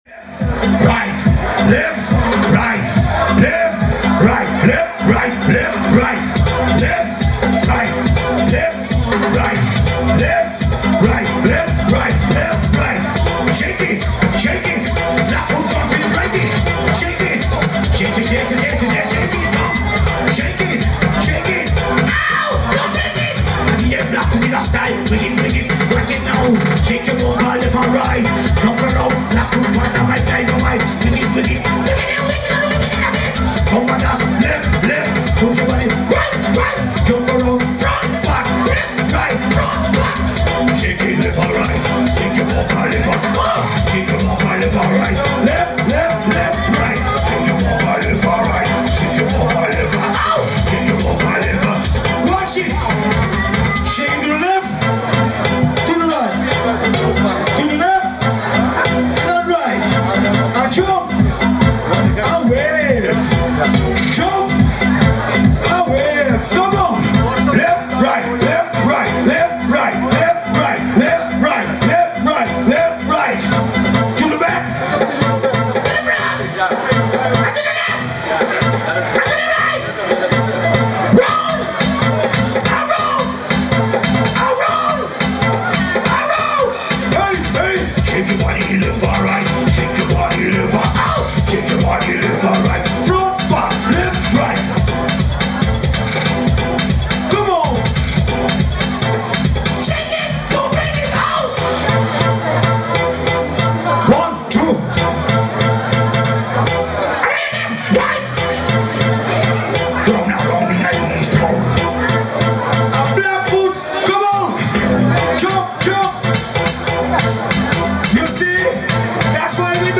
Bubbling Dancehall